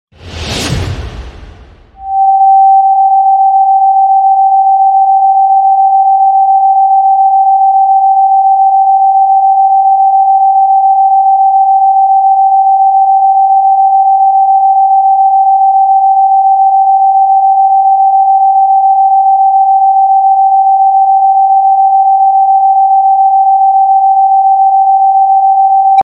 ✨Ever feel like the universe is trying to guide you? 777Hz tunes your energy to receive — luck, clarity, and alignment.